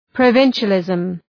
Προφορά
{prə’vınʃə,lızm}